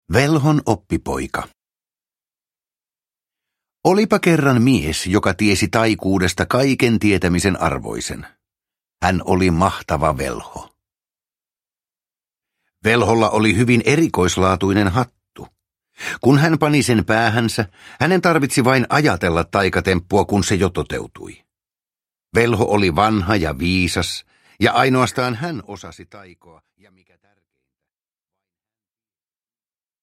Täyttä taikaa – Ljudbok – Laddas ner